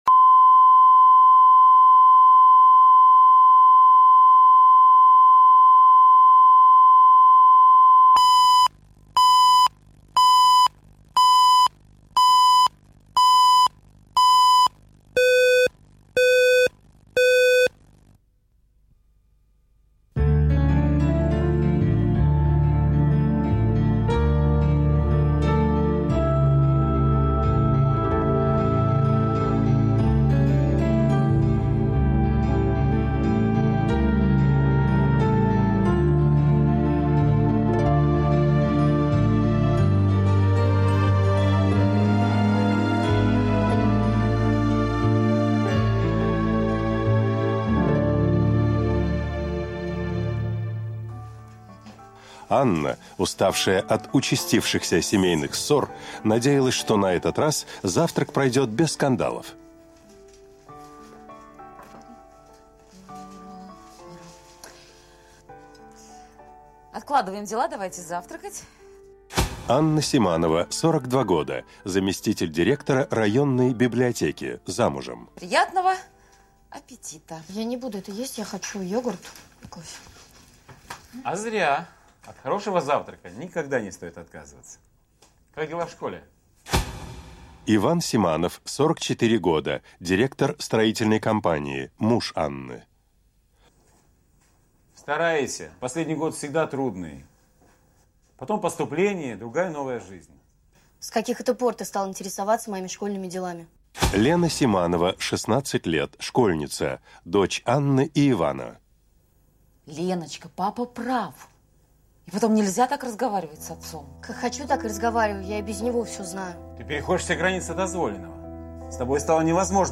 Aудиокнига Учительница Автор Александр Левин.